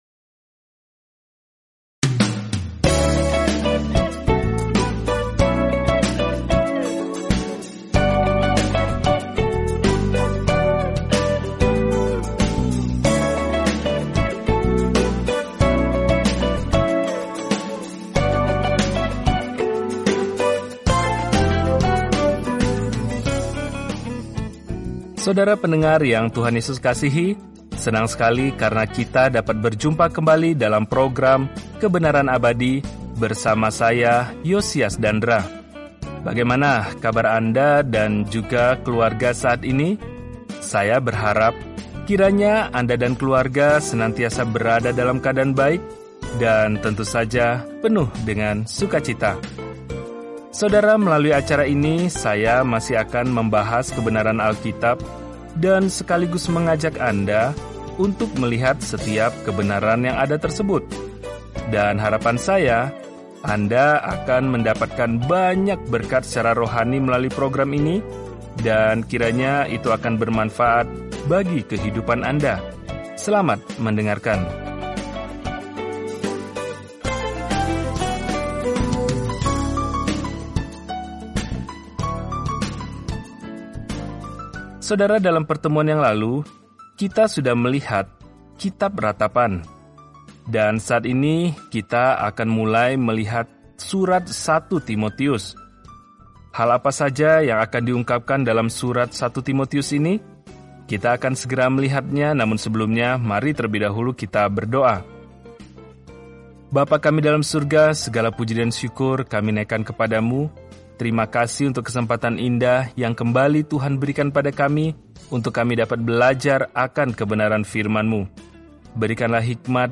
Firman Tuhan, Alkitab 1 Timotius 1:1 Mulai Rencana ini Hari 2 Tentang Rencana ini Surat pertama kepada Timotius memberikan indikasi praktis bahwa seseorang telah diubah oleh Injil – tanda-tanda kesalehan yang sejati. Telusuri 1 Timotius setiap hari sambil mendengarkan pelajaran audio dan membaca ayat-ayat tertentu dari firman Tuhan.